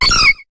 Cri de Mélo dans Pokémon HOME .